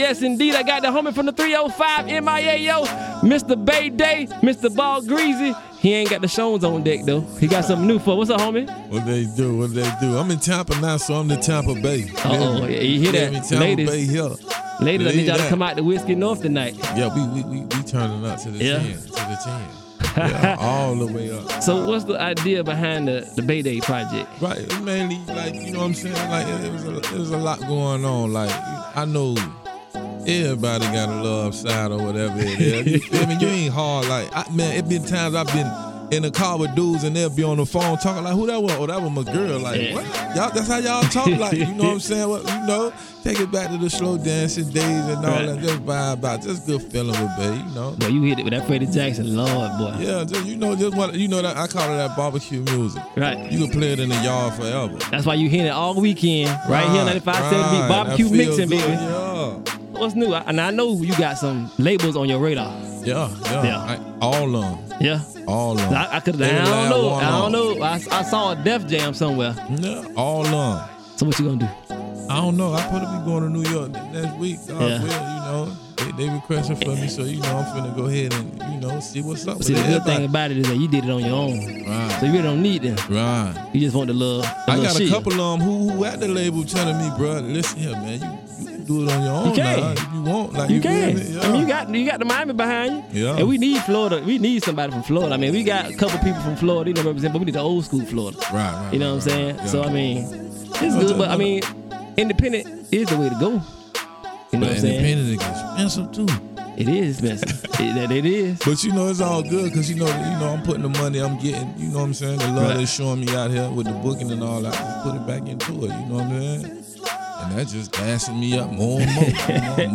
We Discuss All Things DJ. Interviews With Artist, Promoters and Event Planners.